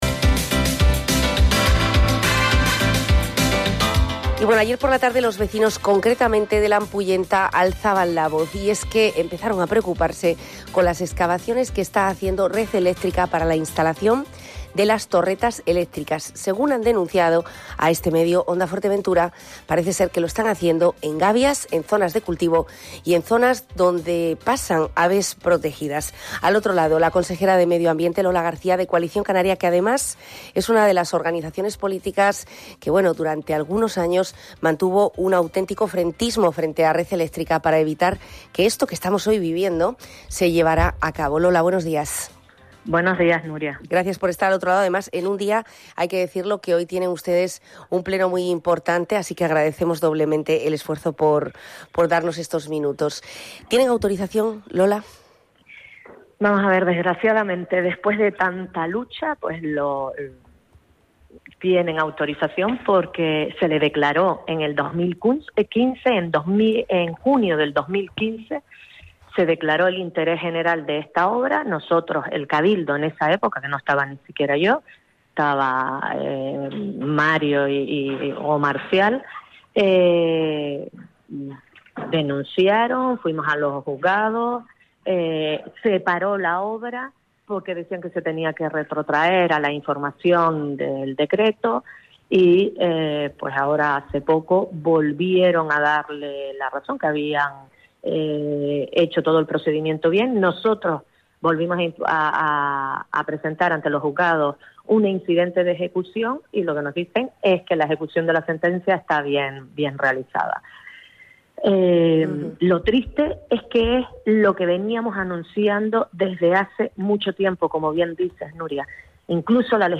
La torretas de alta tensión en suelo protegido. De ello ha hablado en El Magacín de Onda Fuerteventura la Vicepresidenta del Cabildo de Fuerteventura Lola García. Con el apoyo total a los vecinos que reclaman mayor control y protección porque se trata de terrenos con gavias y cultivos, desde el Cabildo de Fuerteventura se va a seguir luchando porque no sean las grandes empresas las que puedan disponer del territorio.